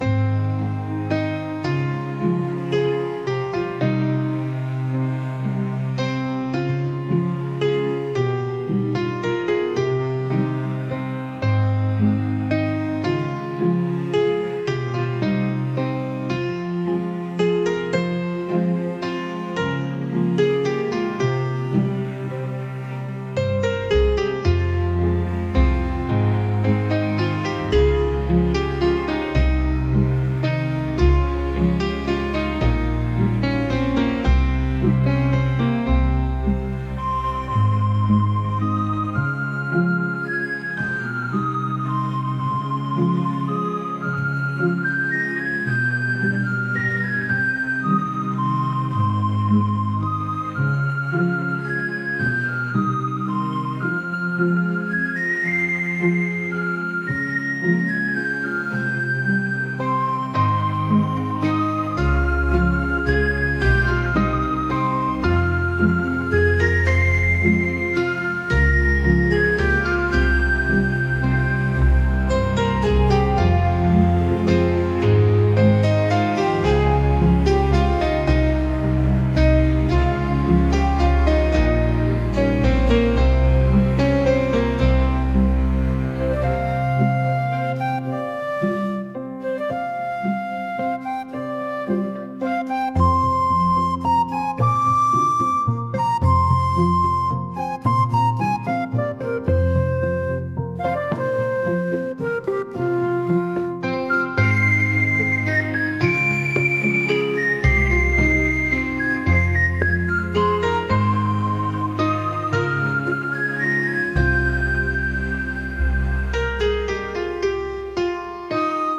「悲しい」